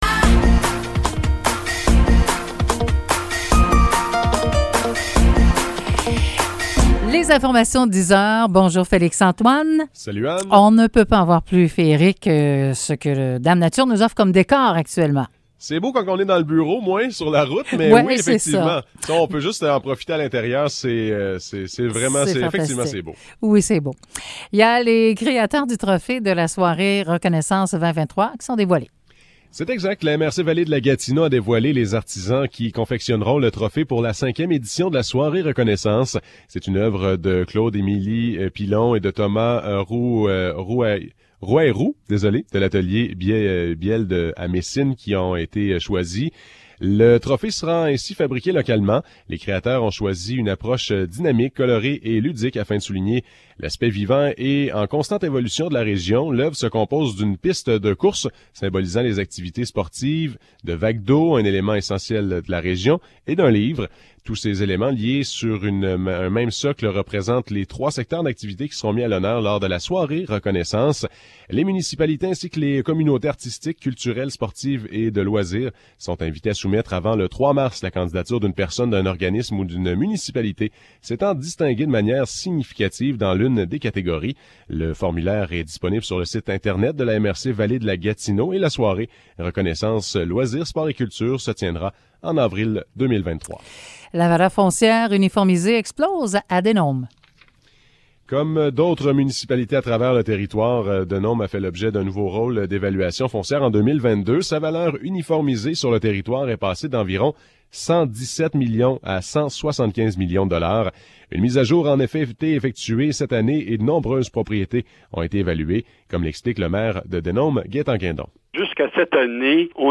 Nouvelles locales - 21 décembre 2022 - 10 h